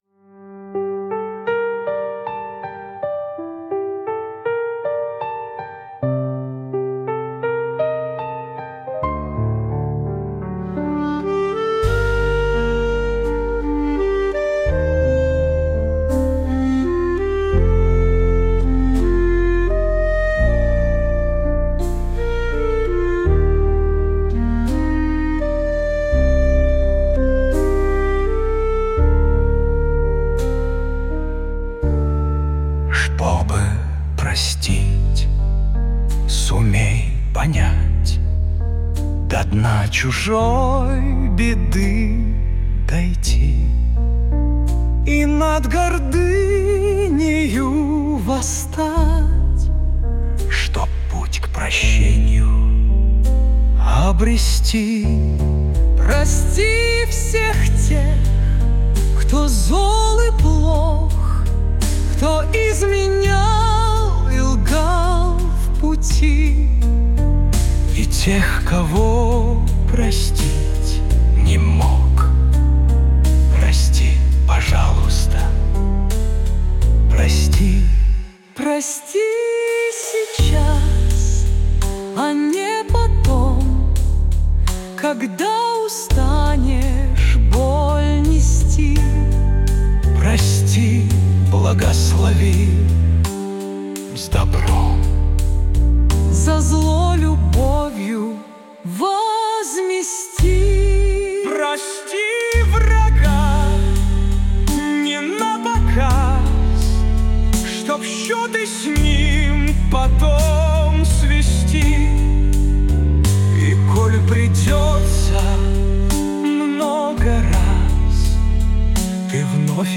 Для Медитаций